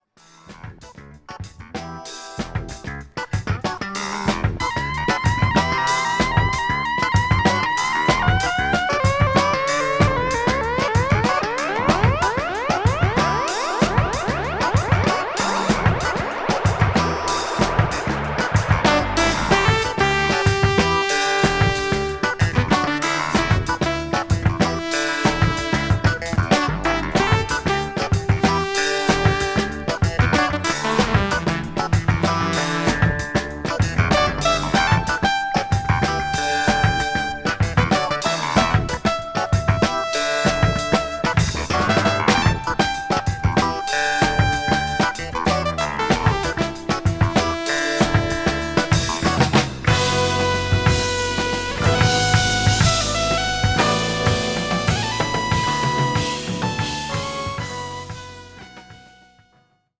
JAZZ / JAZZ FUNK / FUSION